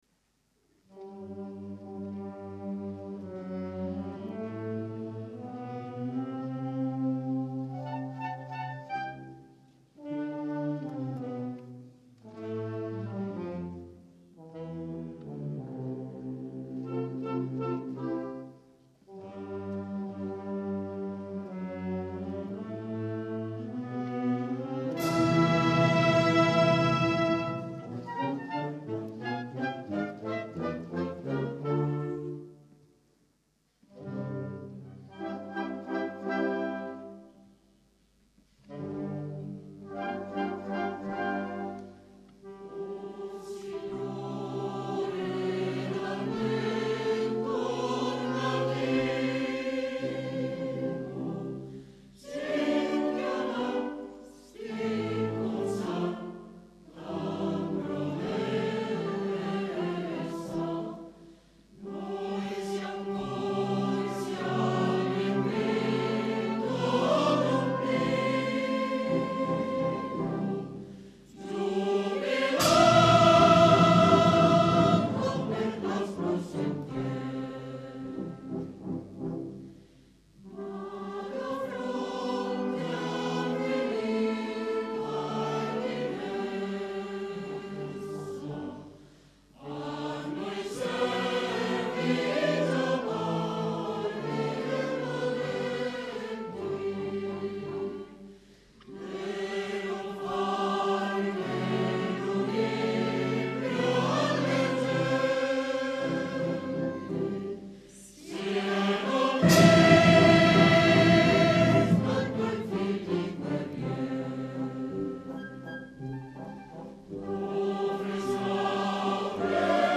I Brani Musicali sono stati registrati al Teatro "A. Bonci" di Cesena il 18 Febbraio 2001 durante il
CONCERTO LIRICO
CORALE BANDISTICO
Il Coro Lirico Città di Cesena
Banda "Città di Cesena"